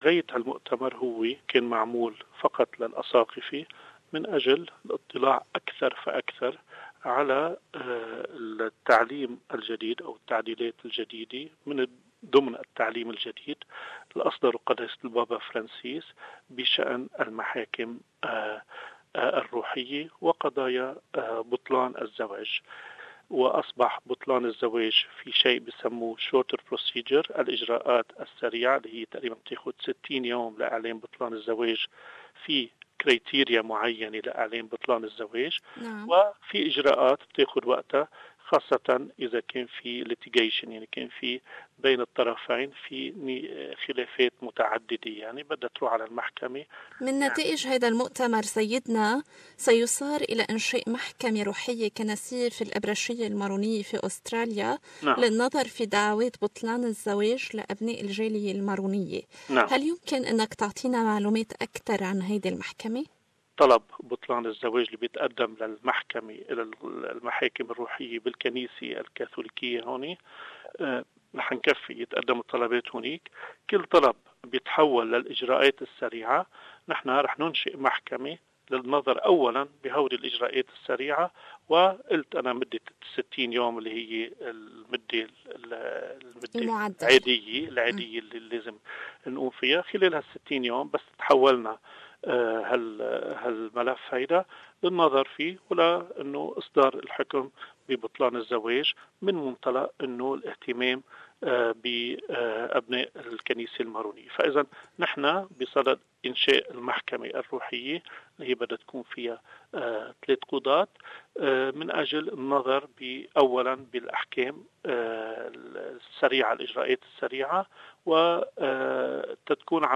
In an Interview with SBS Arabic 24, Bishop Antoine Charbel Tarabay spoke about the new spiritual court in the Maronite Church in Australia. He also asked the Australian Government to be more flexible with the refugees crisis.